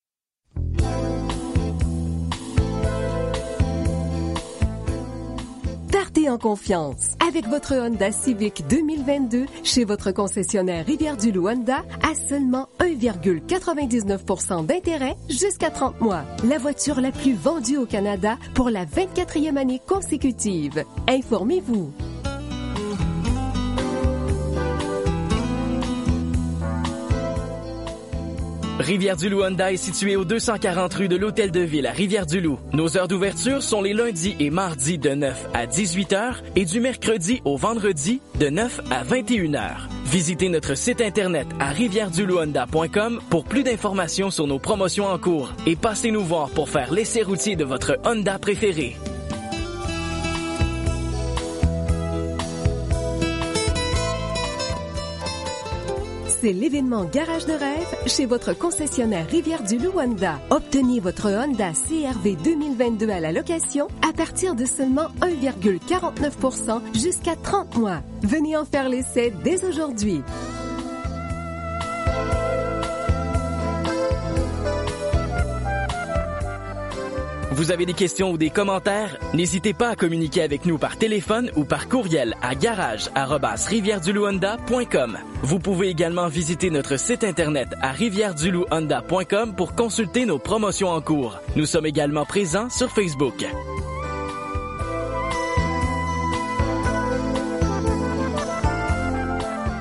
Comprend un message d’attente, la rédaction, la narration et le montage musical (pas de contrat et pas de versement mensuel)